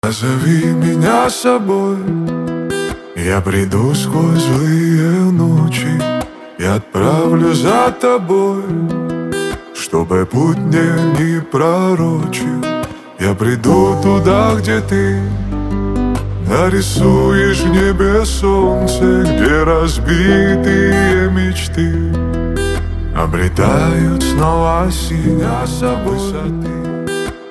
поп
красивый мужской голос , гитара